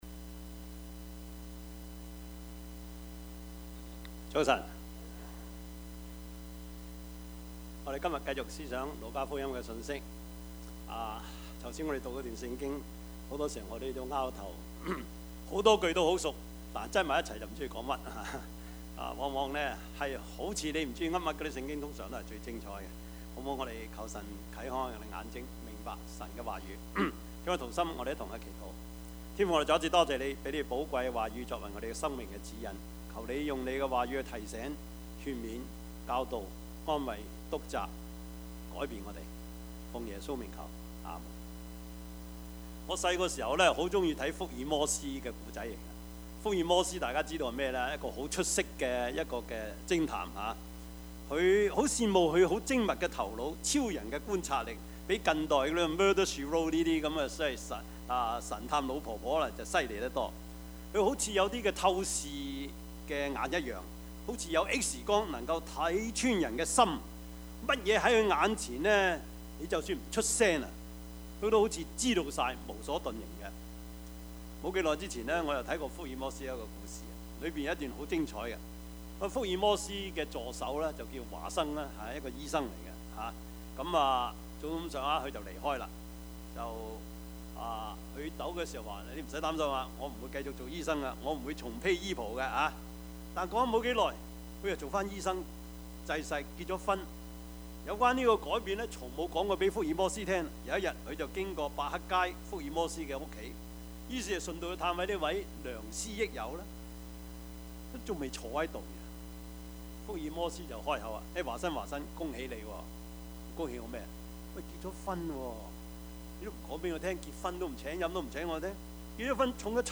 Passage: 路加福音十二:49-59 Service Type: 主日崇拜
Topics: 主日證道 « 儆醒等候 苦罪懸謎 »